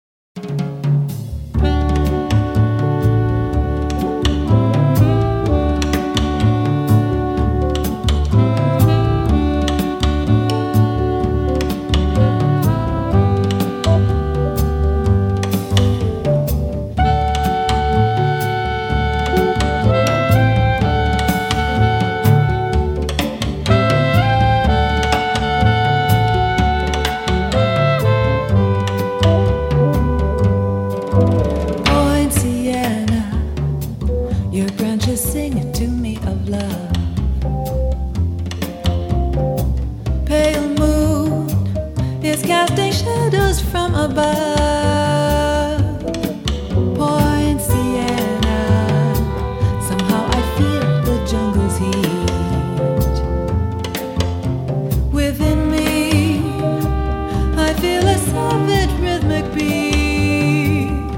guitar, vocals